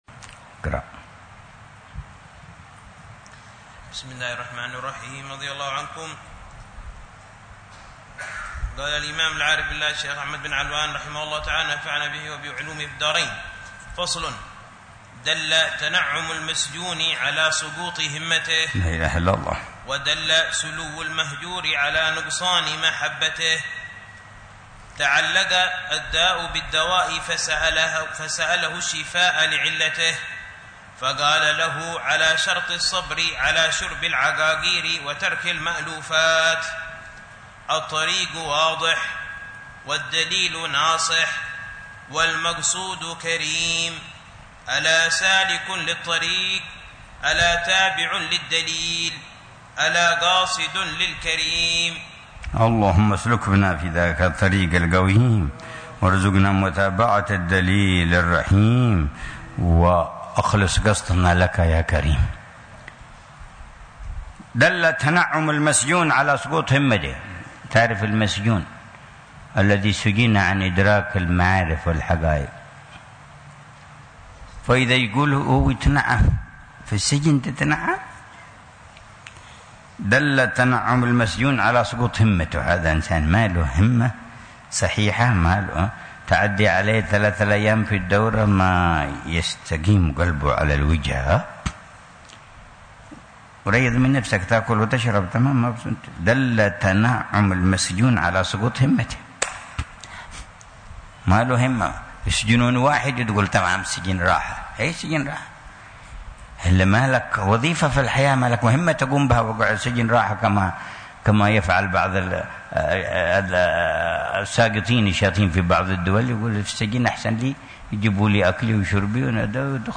شرح الحبيب عمر بن حفيظ على كتاب: التوحيد الأعظم المبلغ من لا يعلم إلى رتبة من يعلم، للإمام الشيخ أحمد بن علوان، ضمن دروس الدورة الرابعة